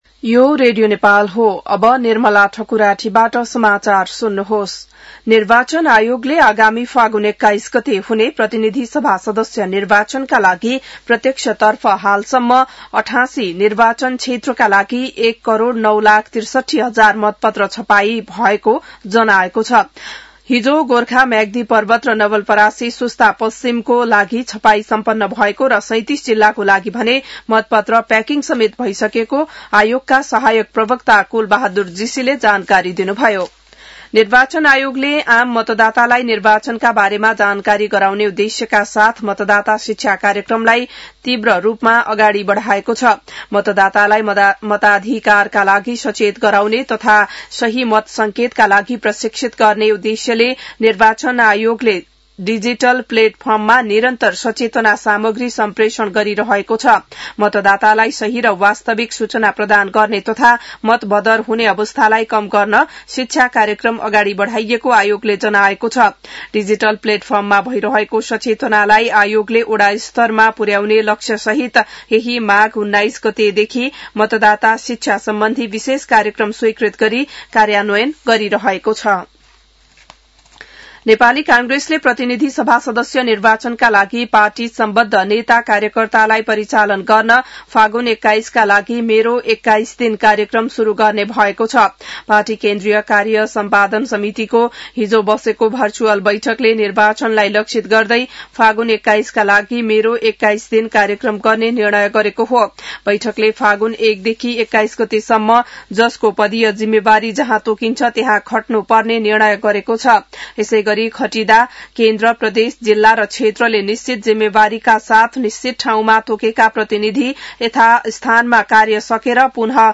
बिहान १० बजेको नेपाली समाचार : २६ माघ , २०८२